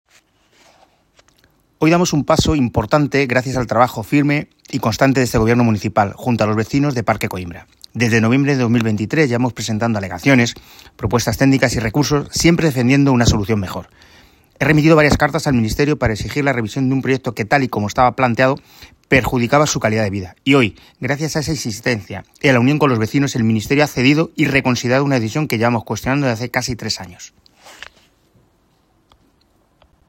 Audio - Manuel Bautista (Alcalde de Móstoles) Pantallas Parque Coimbra
Pantallas Parque Coimbra - Alcalde.m4a